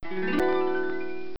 chatSoundMsg.wav